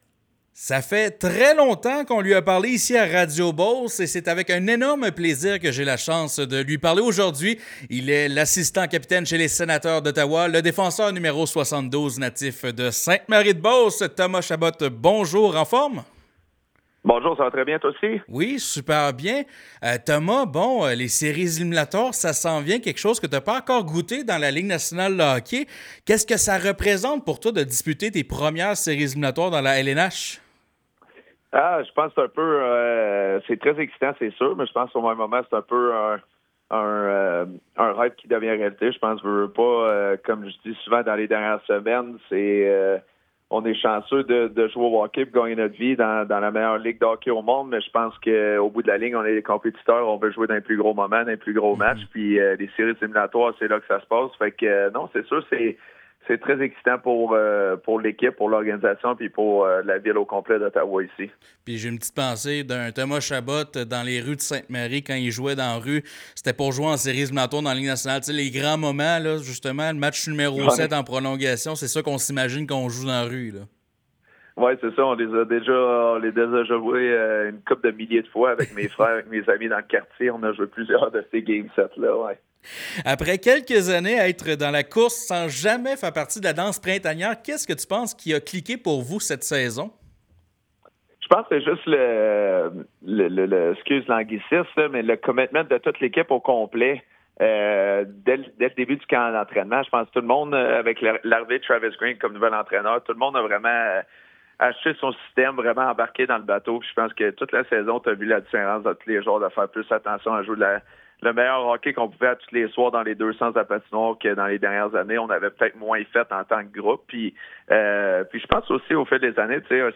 Âgé de 28 ans, le défenseur beauceron s’est confié au micro de Cool FM 103,5 pour discuter de sa dernière saison et des défis qui attendent son équipe en séries.